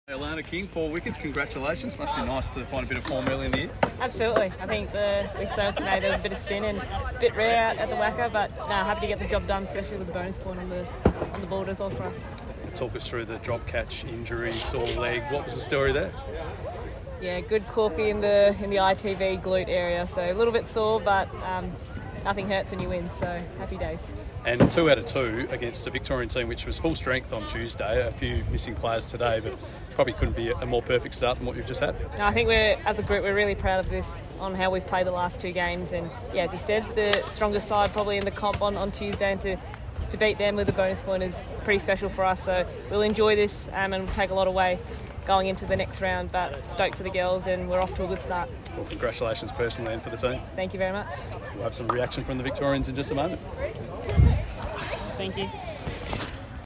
Women's National Cricket League, Alana King (4-32) speaks after Western Australia Women eight-wicket win against Victoria Women in the WNCL